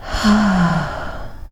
SIGH 4.wav